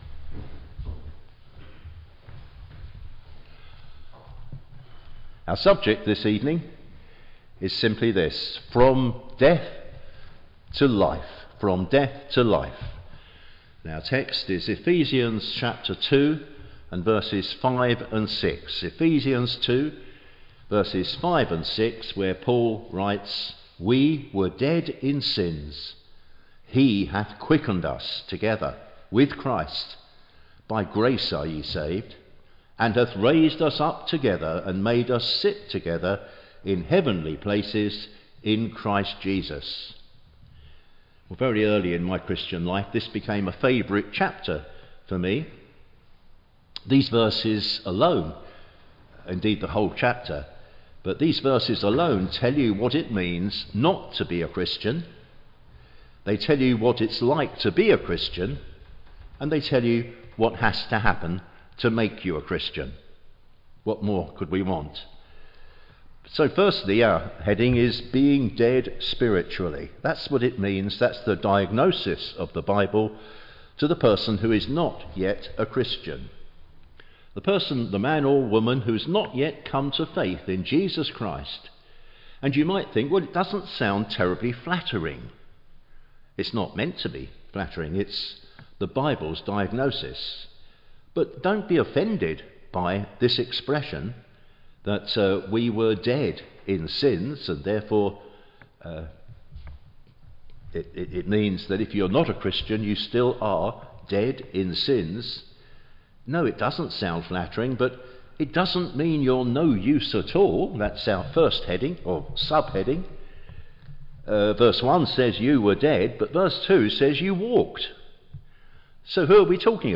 Teaching and Gospel sermons from Ephesians